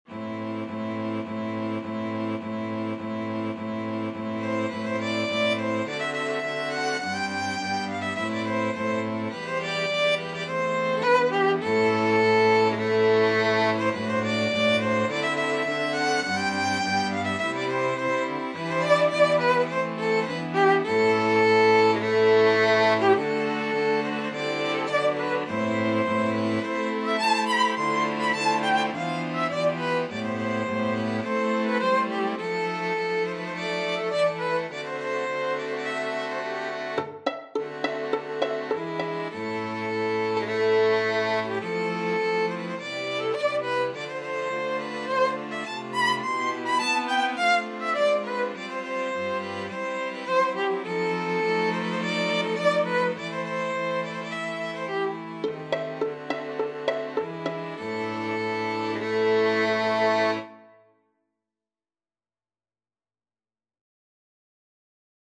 Violin 1Violin 2ViolaCello
2/4 (View more 2/4 Music)
Allegro Moderato ( = 104) (View more music marked Allegro)
Classical (View more Classical String Quartet Music)